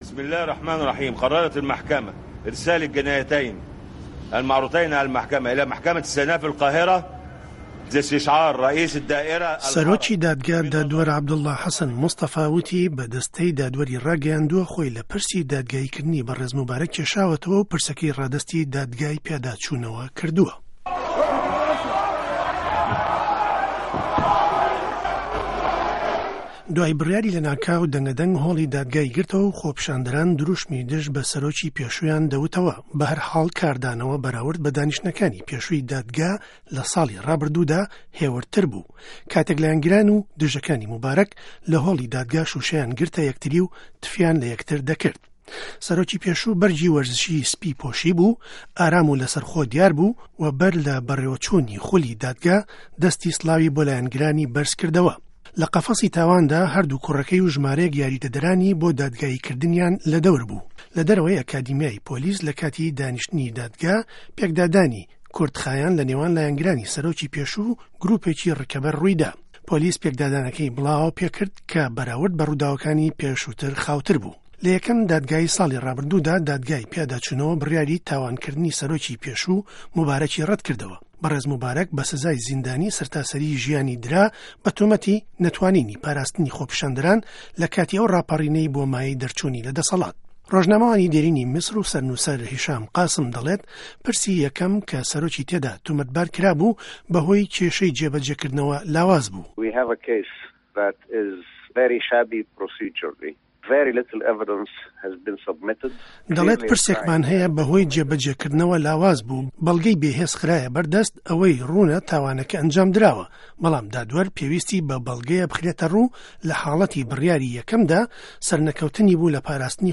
ڕاپۆرت له‌سه‌ر دادگایی کردنی حوسنی موباره‌ک